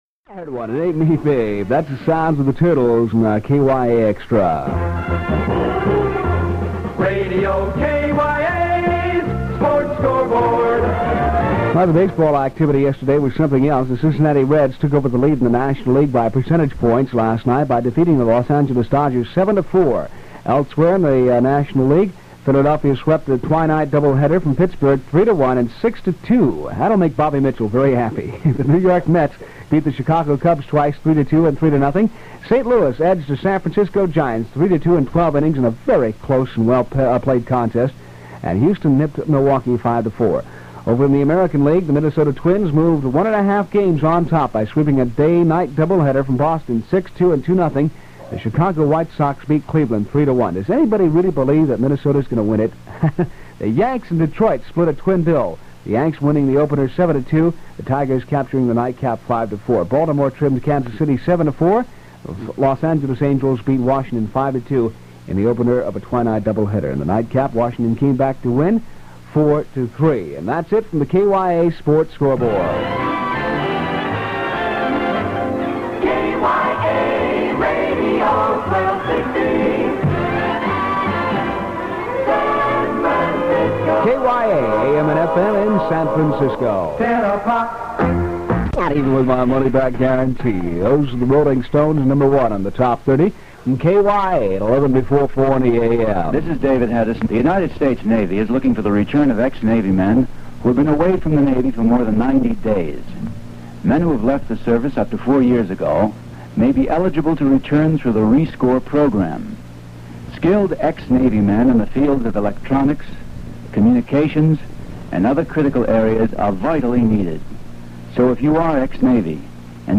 1260 KYA Radio Airchecks Collection - Part One: The 1950s and 1960s |